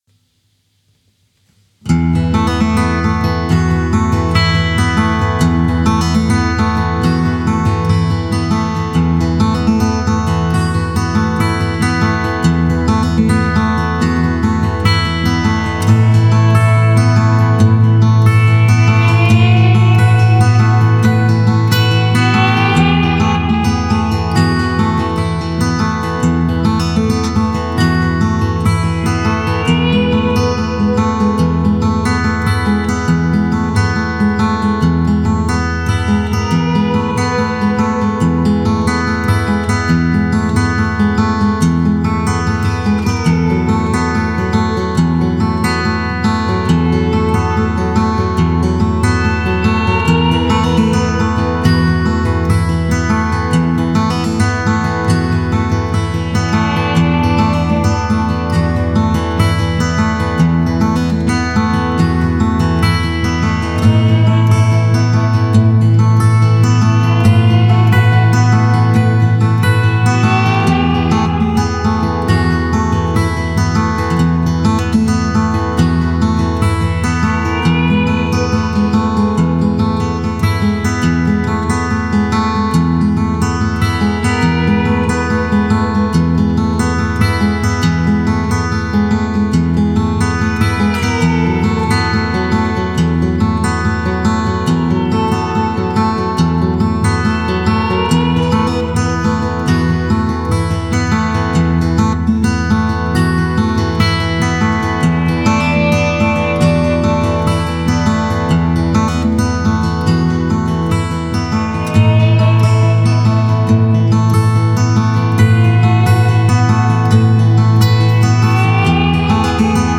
rural sounding music